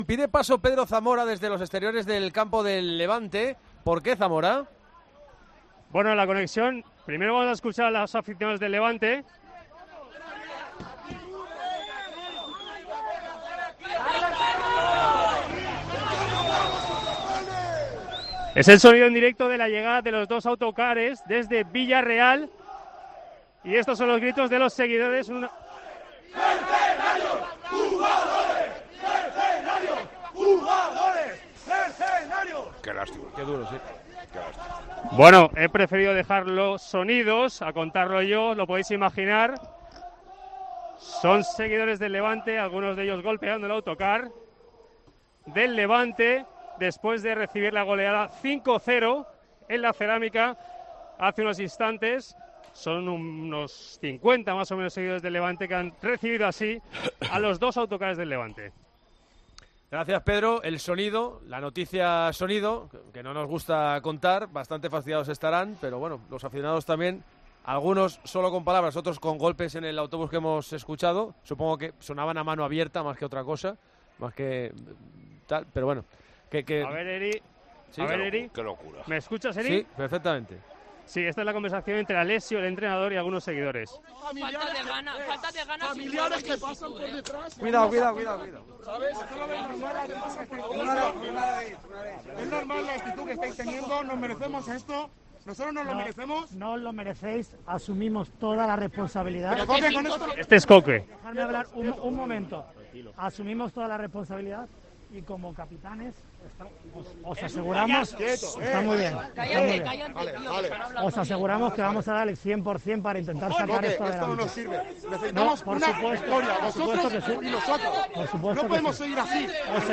Aficionados del Levante reciben al equipo con gritos de "mercenarios" tras caer ante el Villarreal